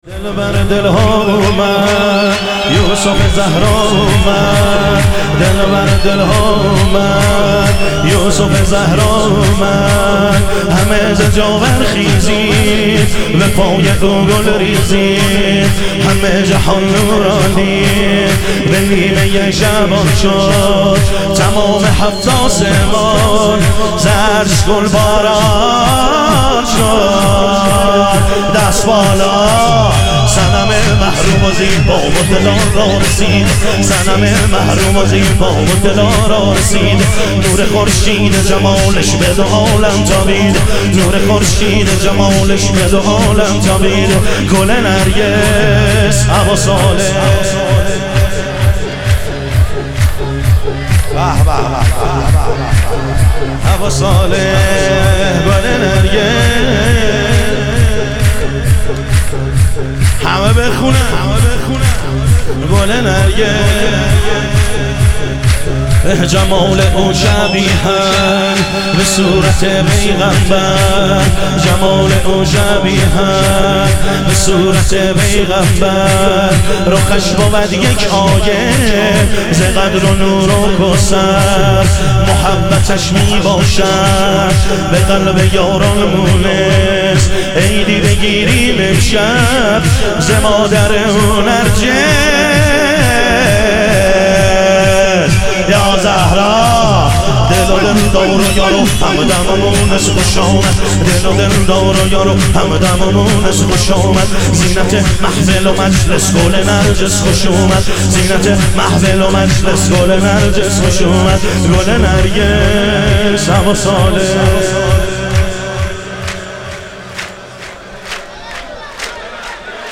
ظهور وجود مقدس حضرت مهدی علیه السلام - شور